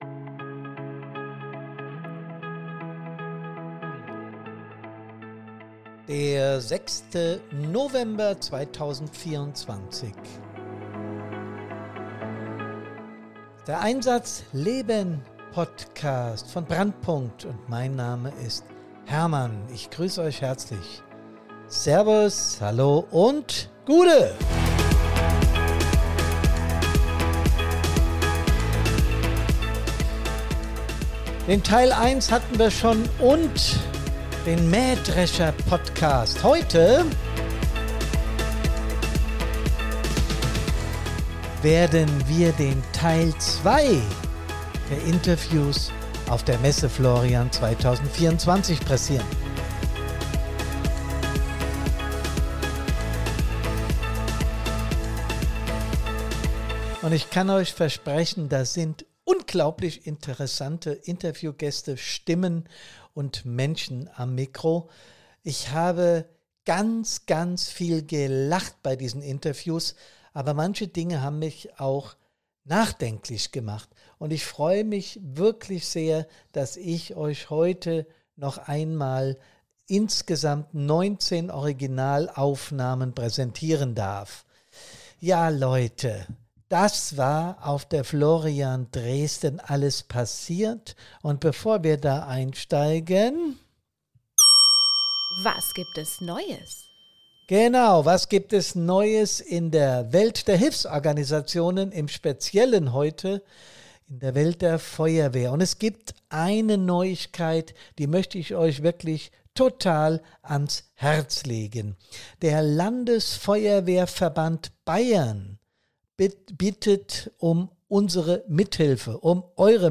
Beschreibung vor 1 Jahr Heute hört ihr Teil 2 der Einsatzgeschichten, die wir für euch auf der diesjährigen Messe Florian eingefangen haben.
Es war für alle Beteiligten ein großer Spaß, sich vor ein Mikrofon zu stellen und ein kurzes Interview zu geben. Trotz leichter Nervosität waren die Resultate klasse und vor allem aus Sicht der Teilnehmer:innen hochinteressant.